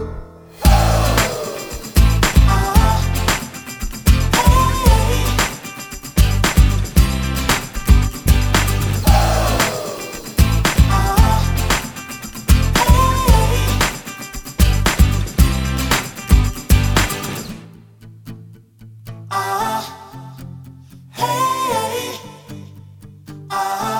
no Backing Vocals Duets 3:43 Buy £1.50